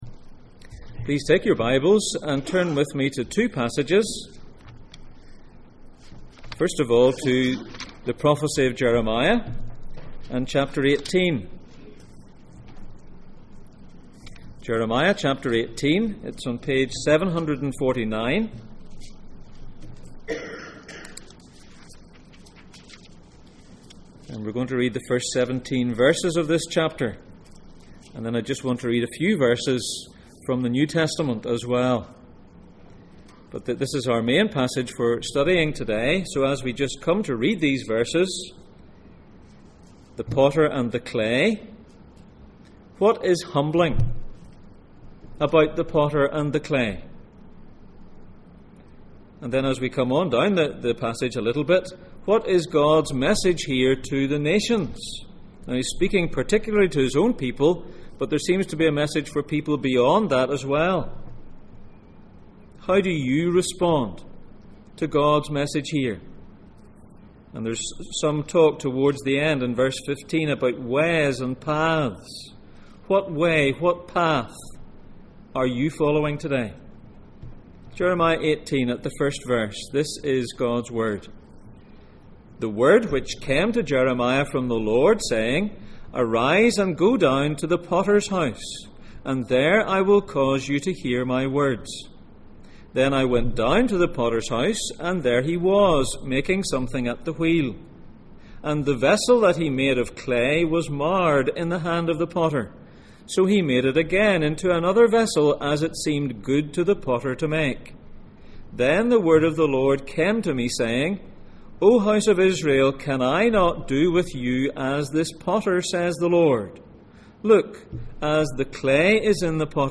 The highs and lows of Jeremiah Passage: Jeremiah 18:1-17, Romans 9:18-24, Jeremiah 1:9-10 Service Type: Sunday Morning